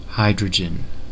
Ääntäminen
IPA : /ˈhaɪ.dɹə.dʒən/